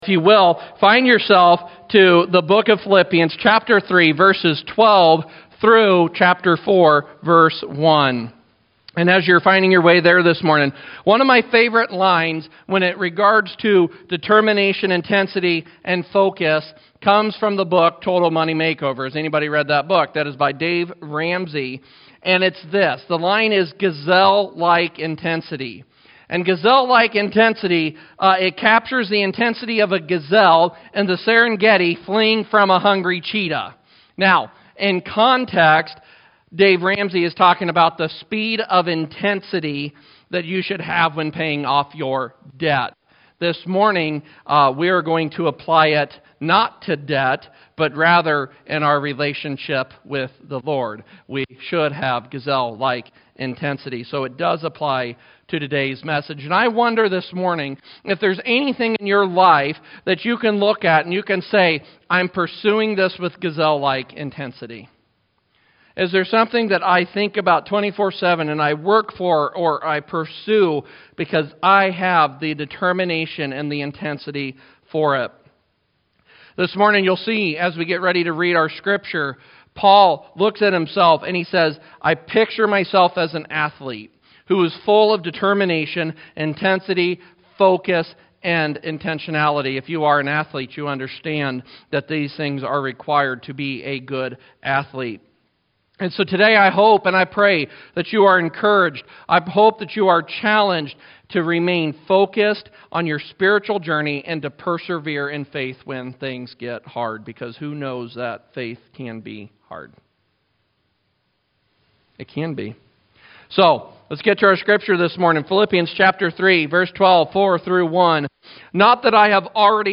Sermons | Engage Church